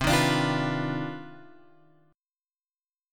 C Minor Major 11th
CmM11 chord {8 6 x 7 6 7} chord